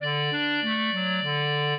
clarinet
minuet15-9.wav